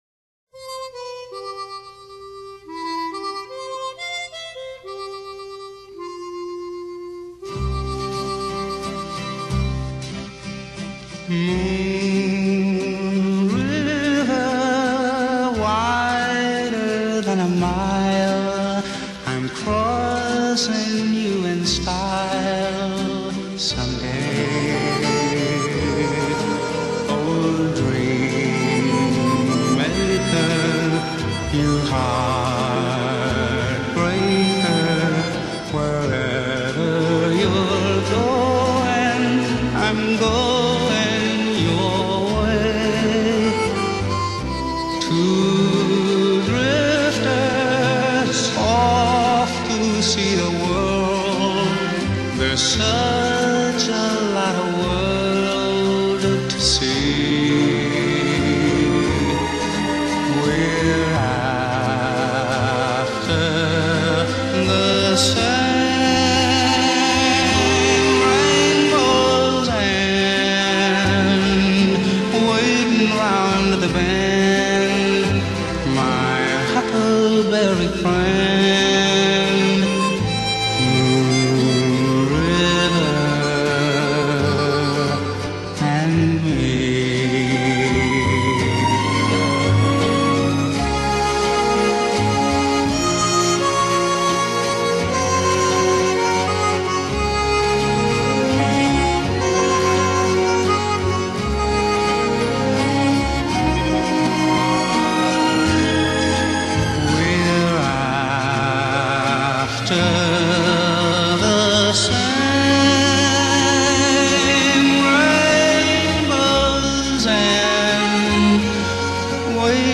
Genre: Dance / Soul / Easy Listening ...
Slow Waltz (01:18:31)